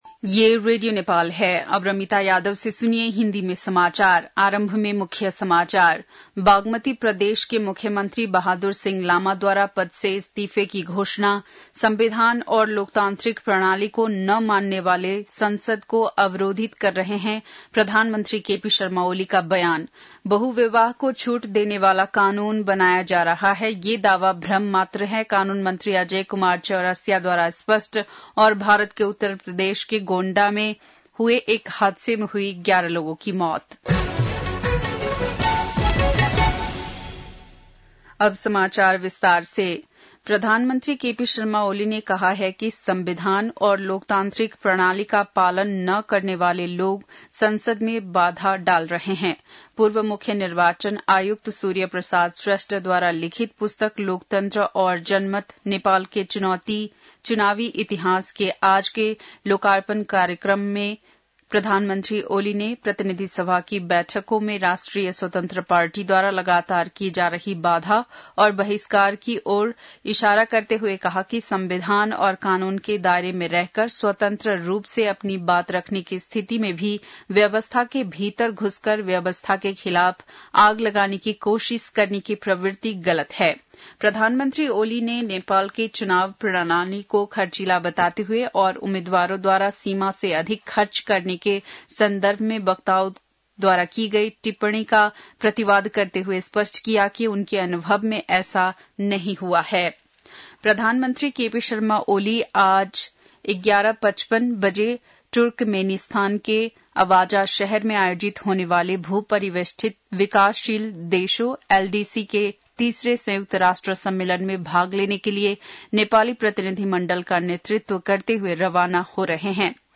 बेलुकी १० बजेको हिन्दी समाचार : १८ साउन , २०८२
10-pm-hindi-news-.mp3